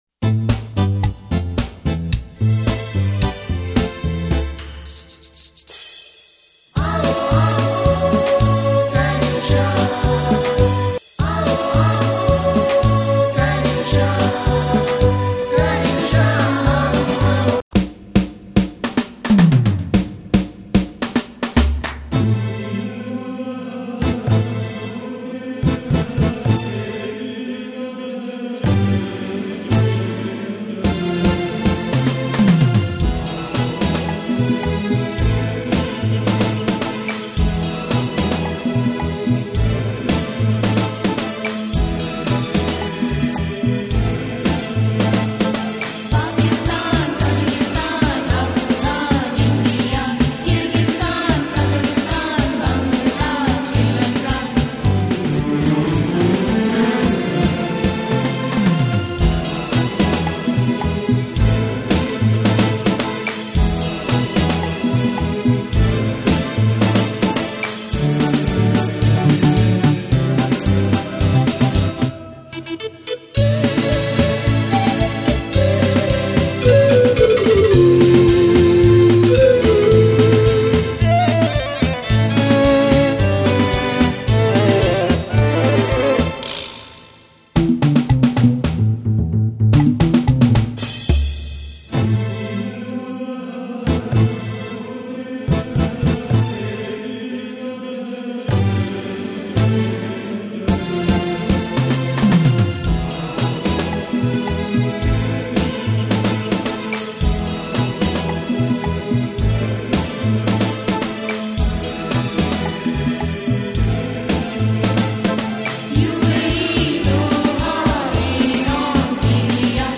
adults/kids choir